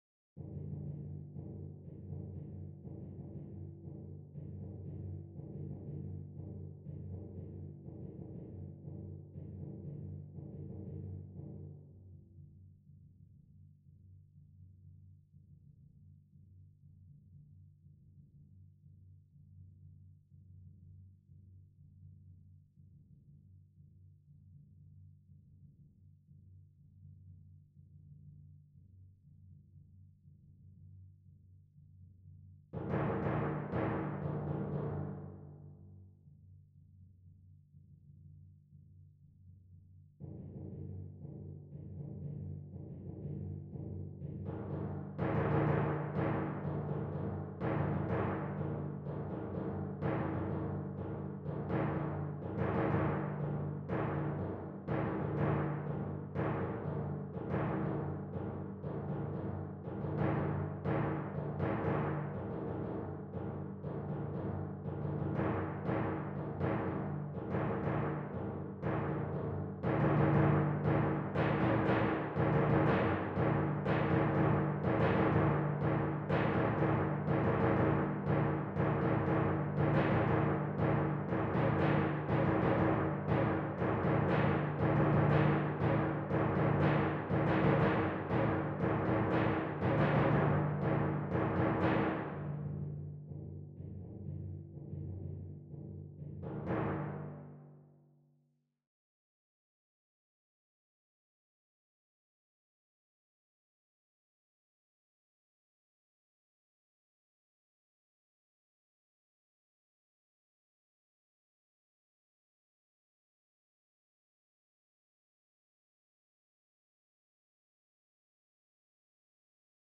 18. Timpani (Timpani/Long hits)
Holst-Mars-43-Timpani_0.mp3